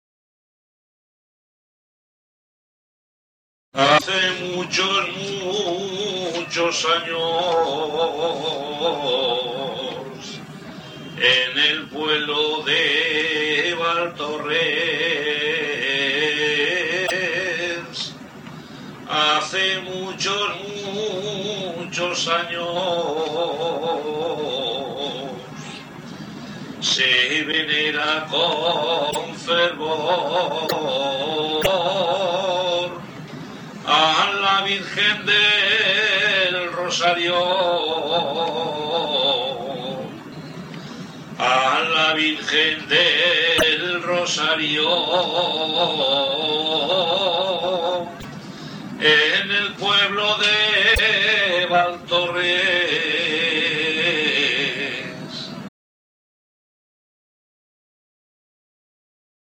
JOTAS